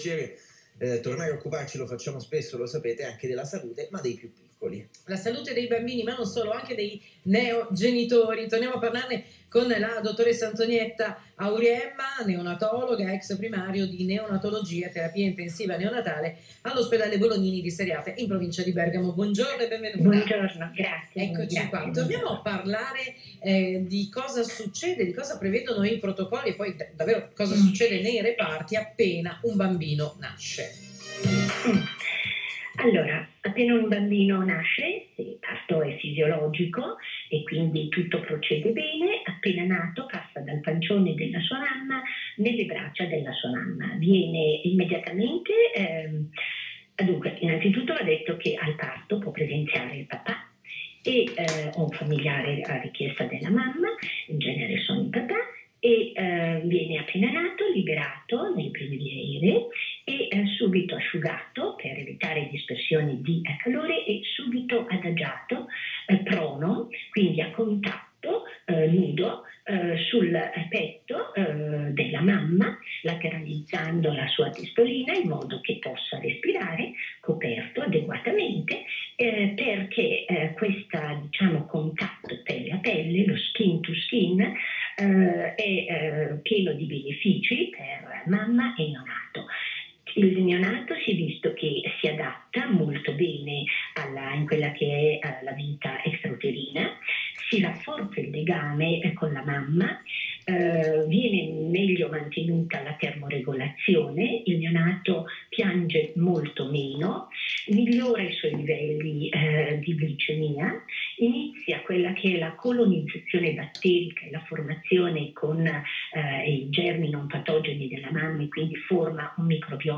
Neonatologia e contatto pelle a pelle: intervista